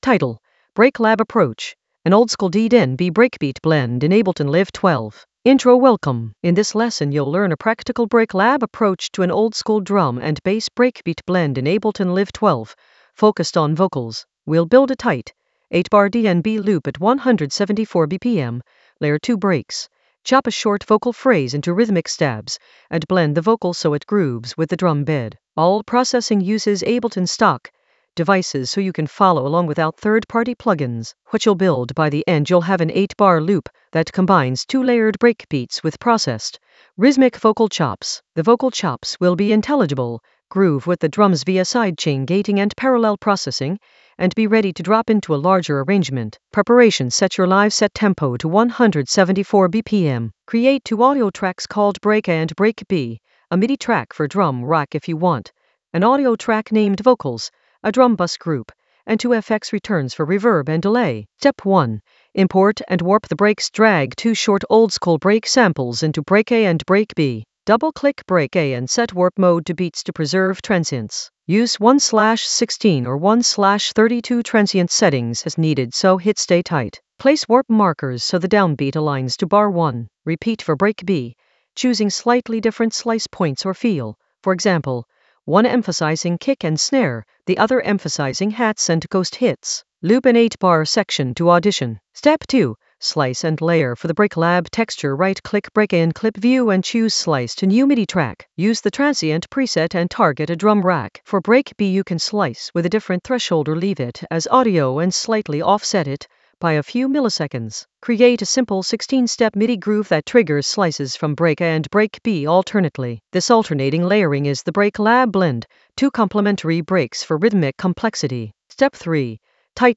An AI-generated beginner Ableton lesson focused on Break Lab approach: an oldskool DnB breakbeat blend in Ableton Live 12 in the Vocals area of drum and bass production.
Narrated lesson audio
The voice track includes the tutorial plus extra teacher commentary.